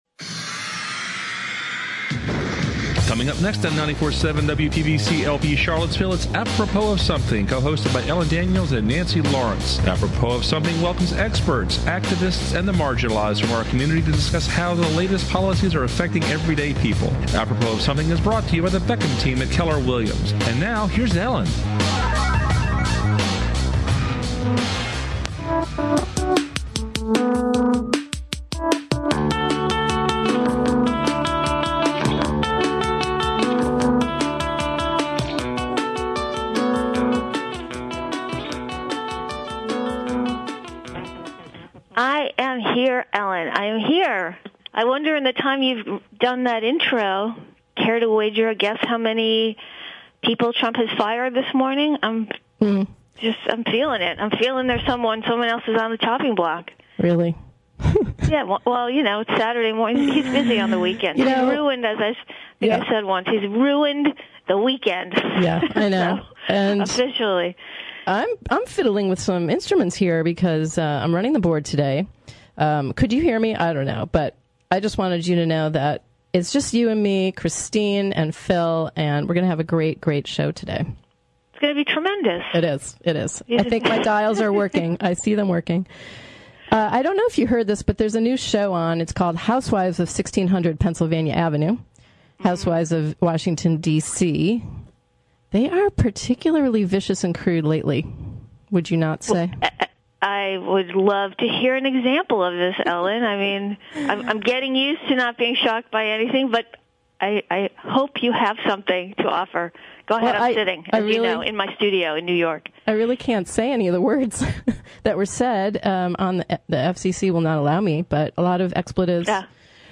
Apropos Of Something seeks out guests who are passionate about the arts, politics and society at-large.